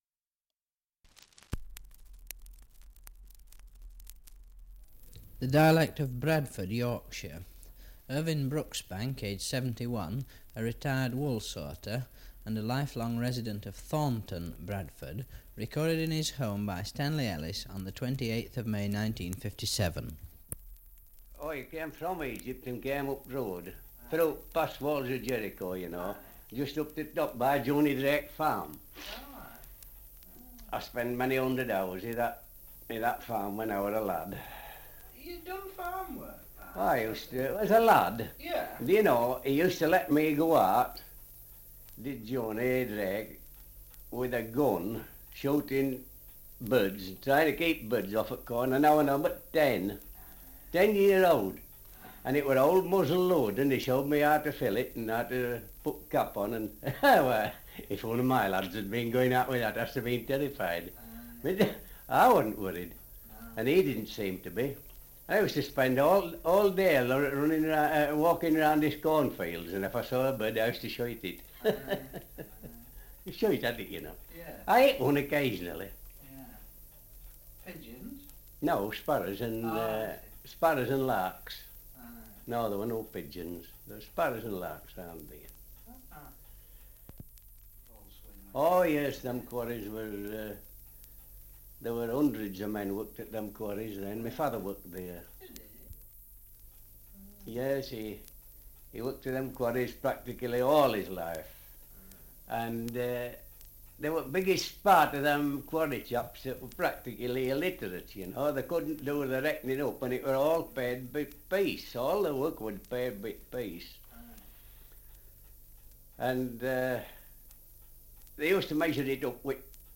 Dialect recording in Thornton, Yorkshire
78 r.p.m., cellulose nitrate on aluminium